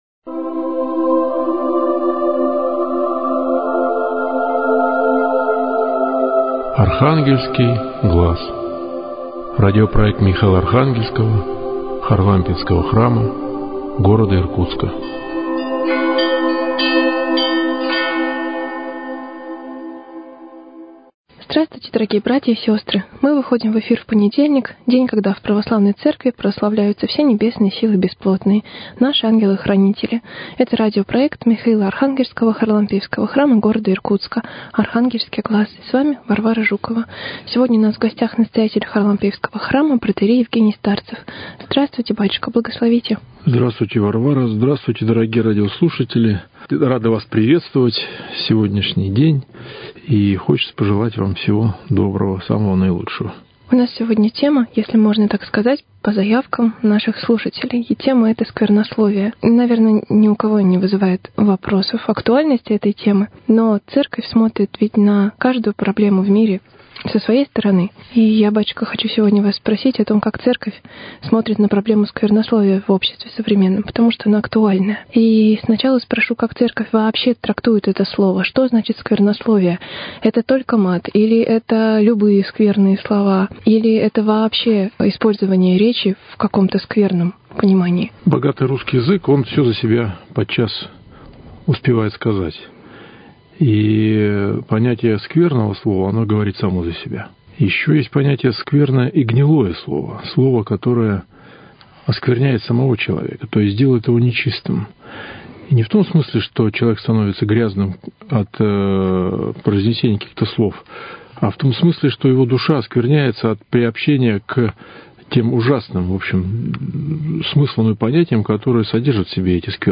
Передача из цикла Михаило – Архангельского Харлампиевского храма.